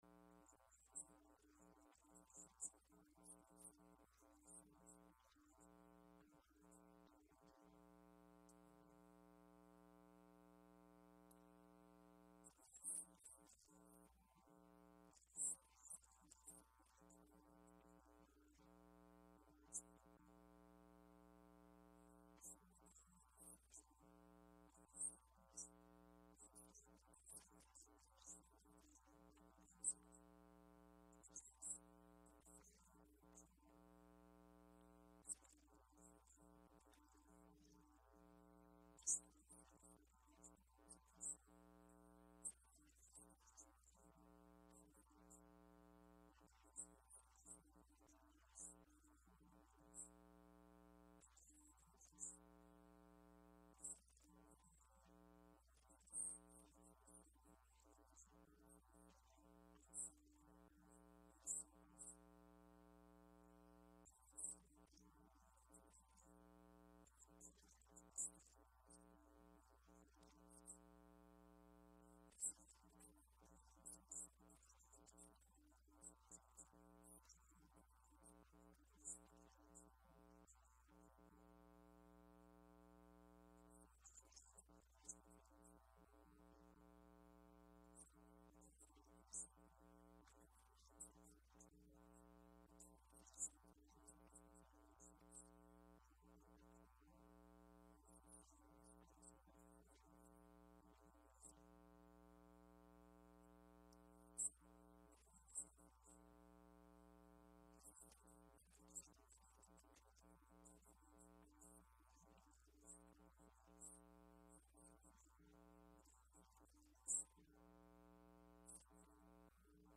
210307 Lent 3BMarch 7, 2021Sermon